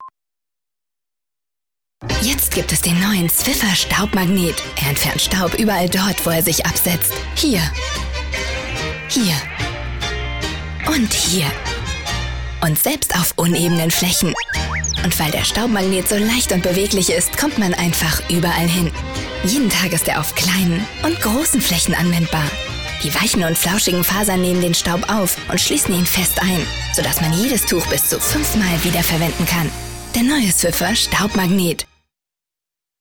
Sprechprobe: Werbung (Muttersprache):
german female voice over artist.